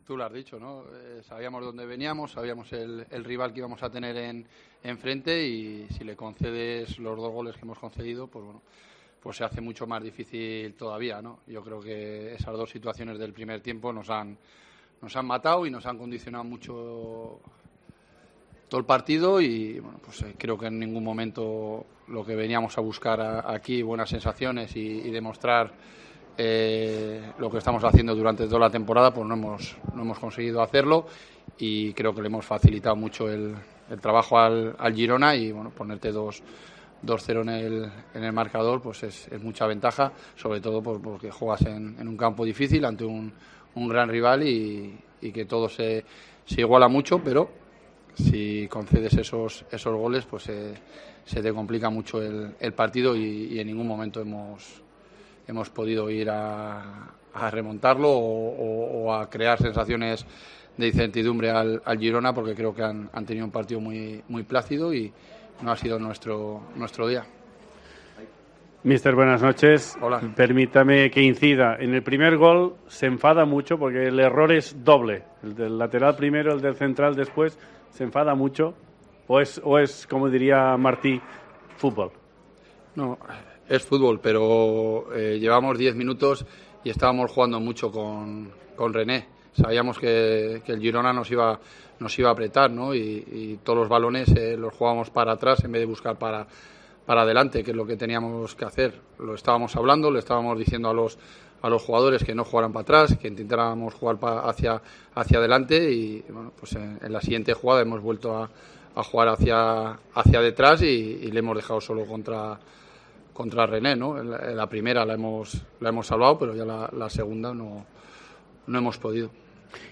POSTPARTIDO
Escucha aquí las palabras de Jon Pérez Bolo, míster de la Deportiva Ponferradina, tras la derrota 2-0 en Montilivi ante el Girona